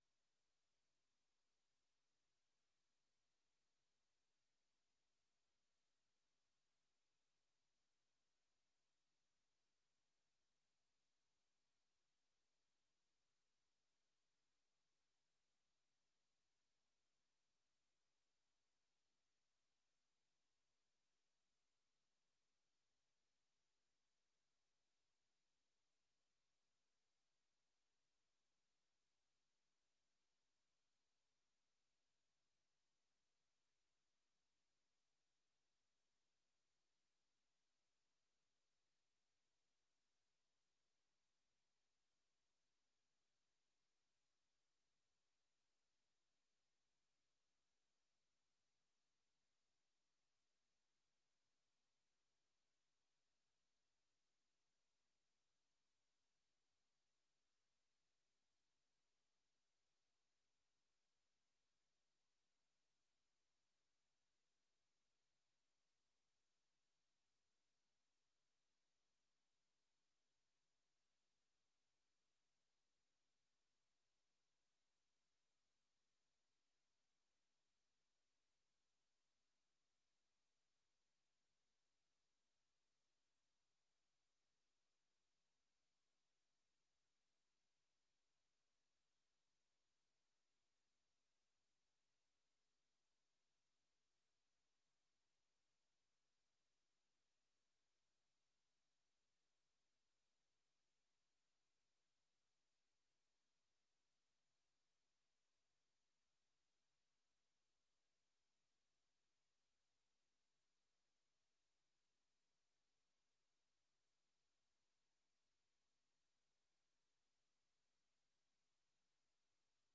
Download de volledige audio van deze vergadering
Locatie: Voorrondezaal Lingewaal Voorzitter: Henk de Man